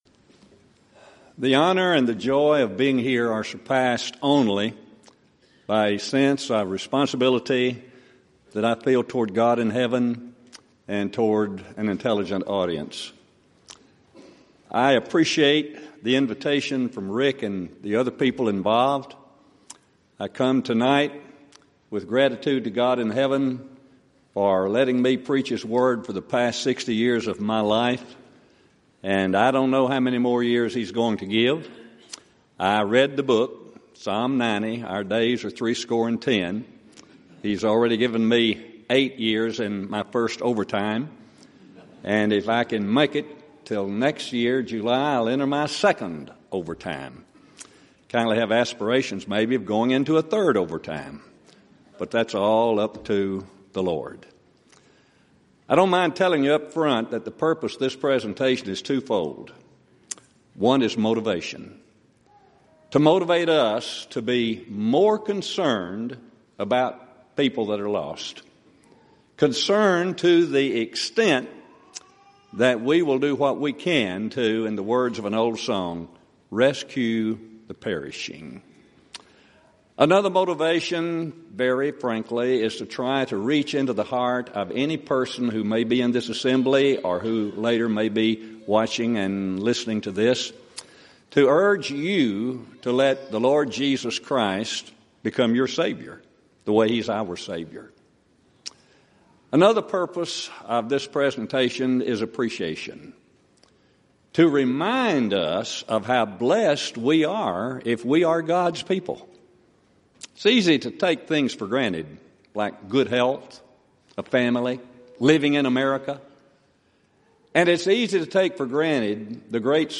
Event: 29th Annual Southwest Lectures Theme/Title: Proclaiming Christ: Called Unto Salvation
lecture